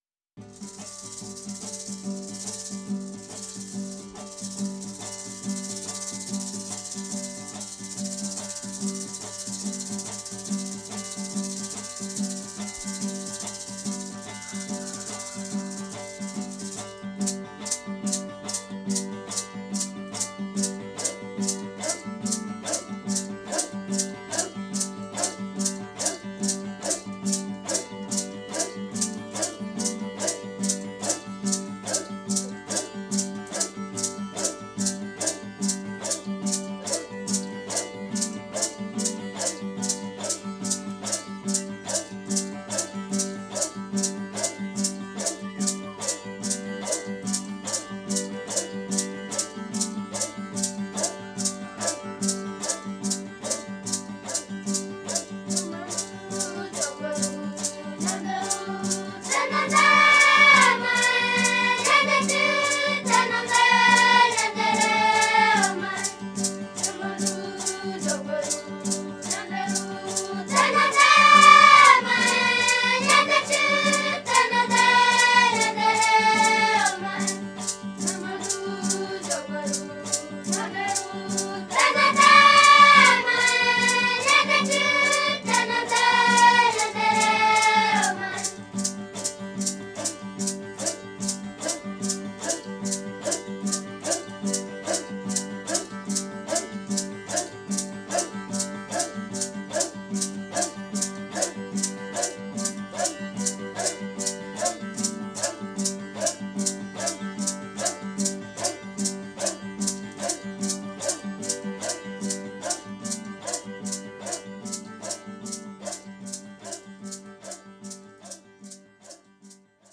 Confira a canção Nhamandu, cantada pelas crianças desse povo: